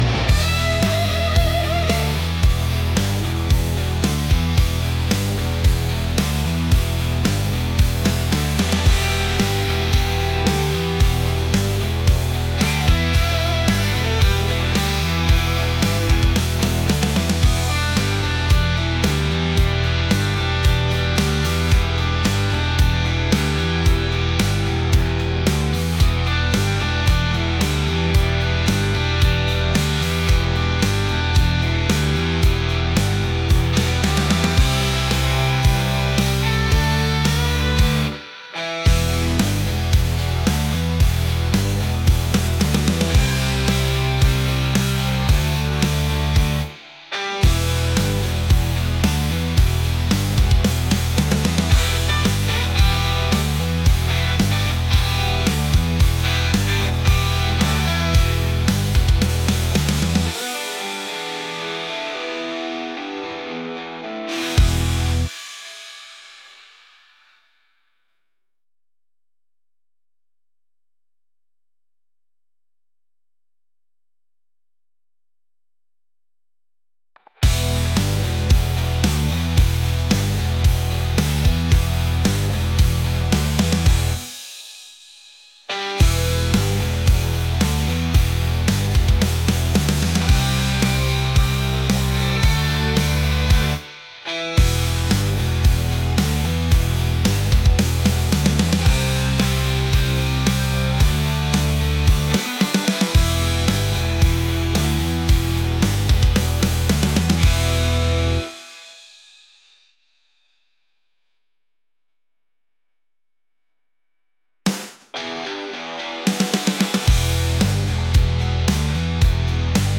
rock | energetic